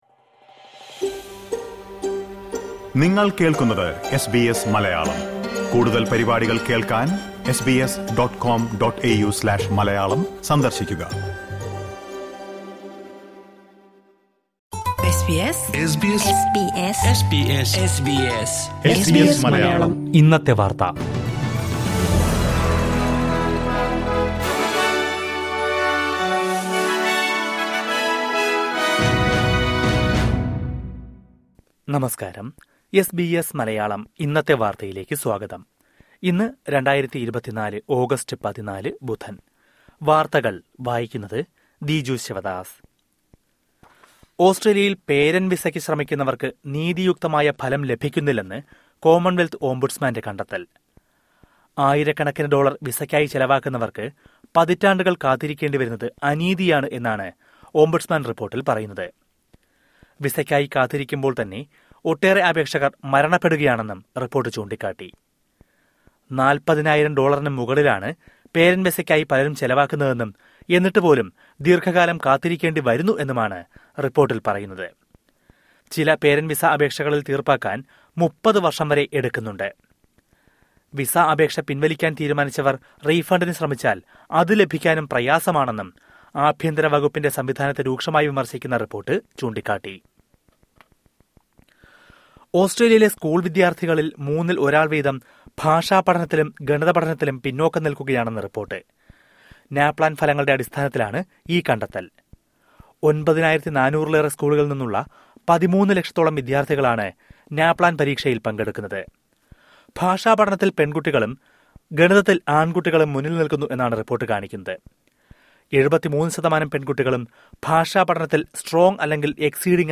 2024 ഓഗസ്റ്റ് 14ലെ ഓസ്‌ട്രേലിയയിലെ ഏറ്റവും പ്രധാന വാര്‍ത്തകള്‍ കേള്‍ക്കാം...